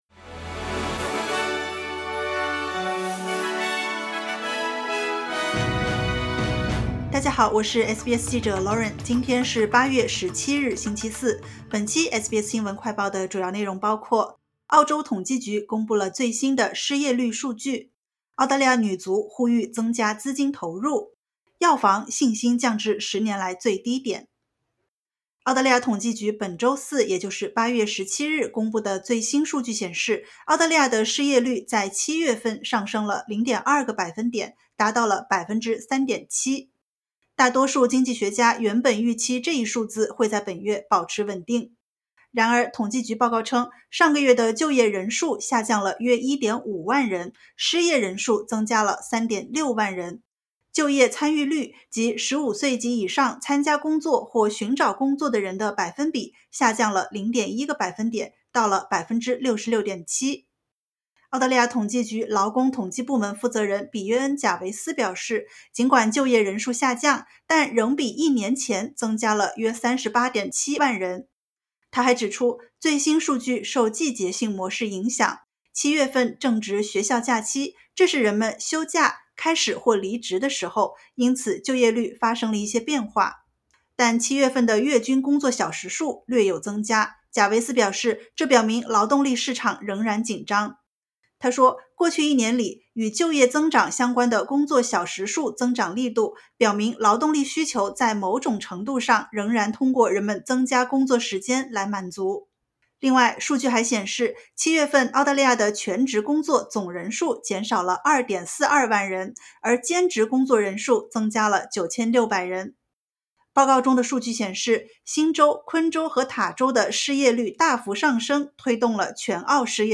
【SBS新闻快报】澳大利亚7月份失业率略升至3.7%